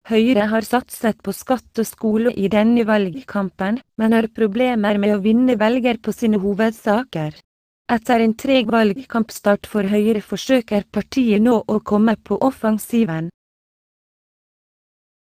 Texte de d�monstration lu par Nora (Nuance RealSpeak; distribu� sur le site de Nextup Technology; femme; norv�gien)